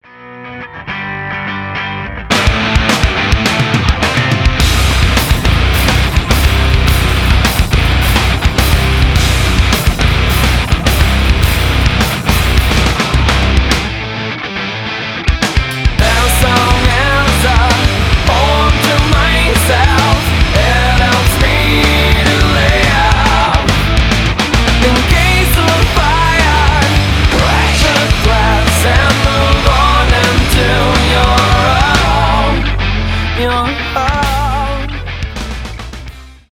alternative metal , nu metal , мощные